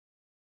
key-press-1.wav